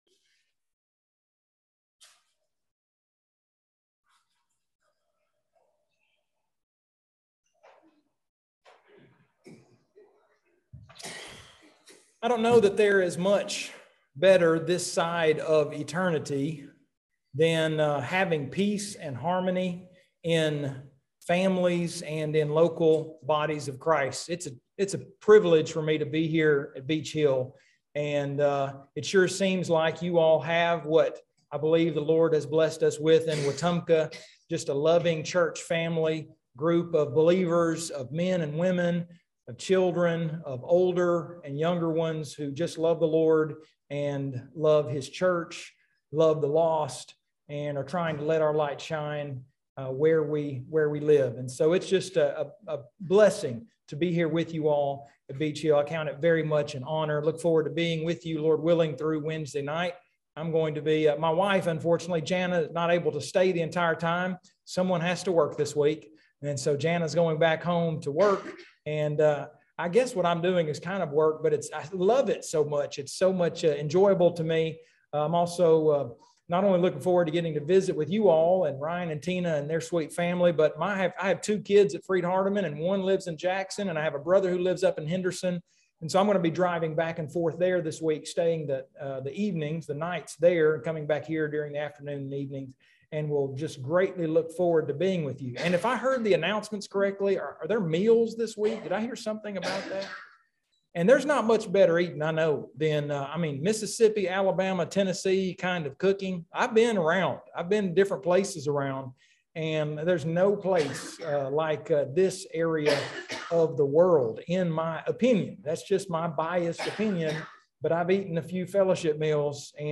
9/11/2022 Gospel Meeting Lesson 2